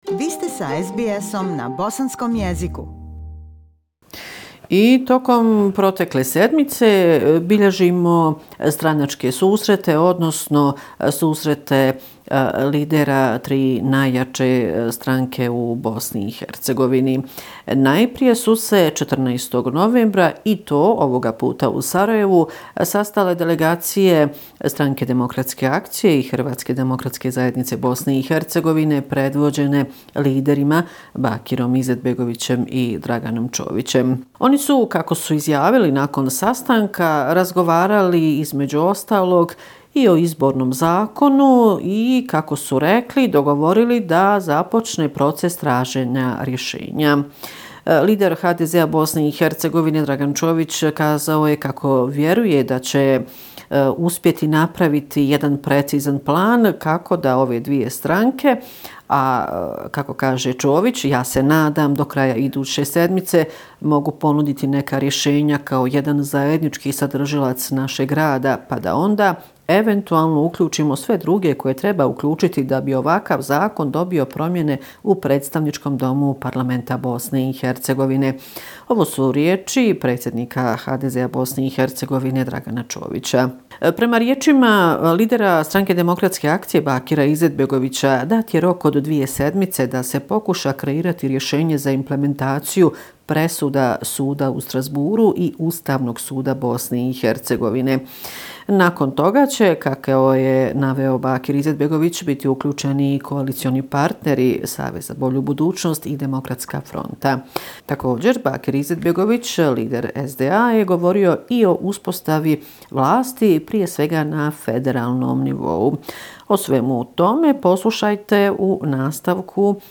Bosnia and Herzegovina - affairs in the country for the last seven day, weekly report November 17, 2019